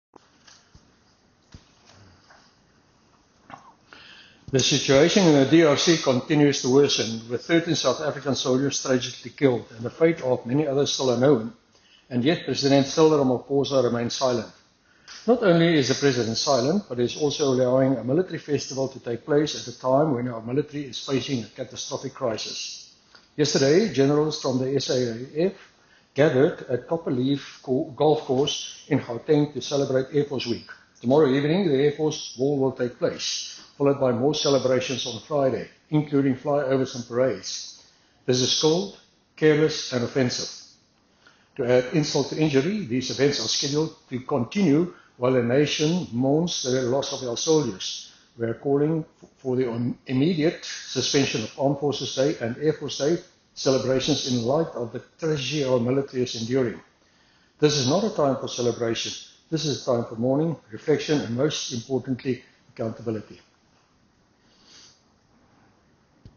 soundbite by Chris Hattingh MP.